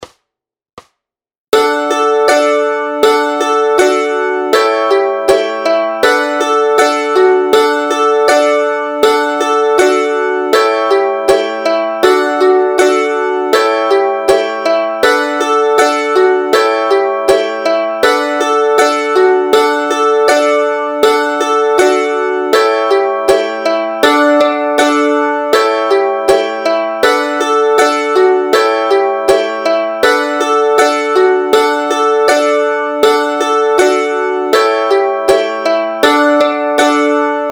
Formát Mandolínové album
Hudební žánr Lidovky